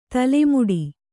♪ tale muḍi